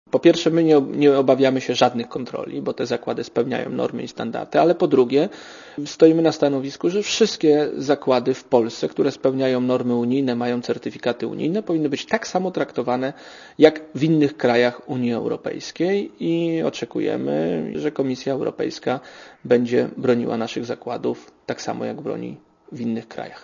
* Mówi minister Janusz Olejniczak*